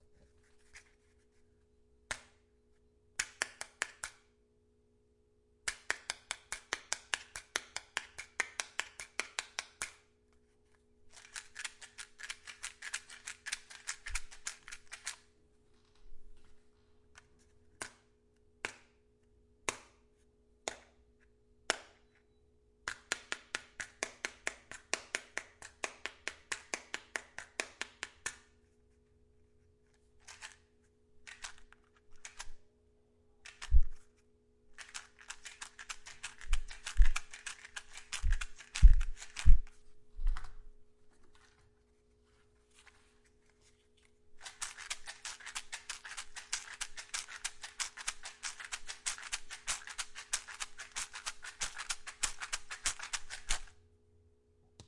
巴西打击乐 " 磷盒 - 声音 - 淘声网 - 免费音效素材资源|视频游戏配乐下载
一盒火柴通常用于桑巴舞，以制作节奏模式。 用Zoom H5n录制